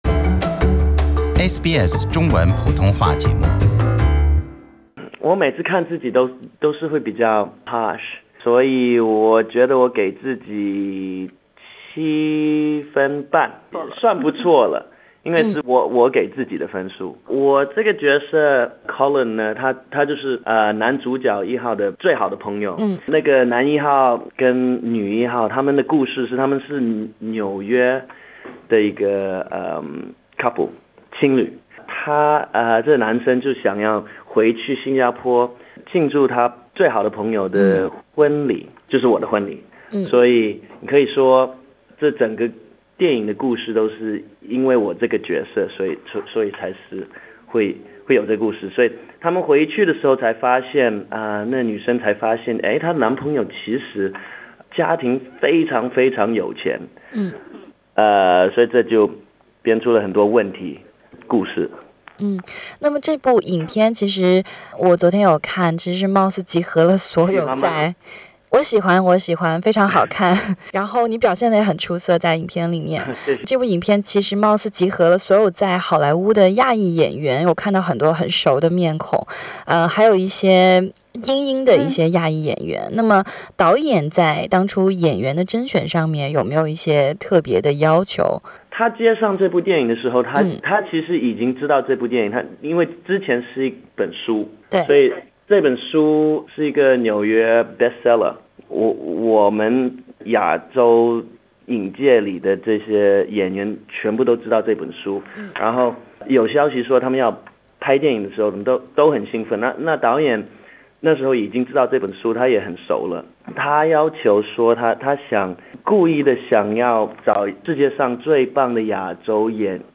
好莱坞全亚裔阵容电影《Crazy Rich Asians》全澳上映：专访Collin饰演者，墨尔本华裔演员Chris Pang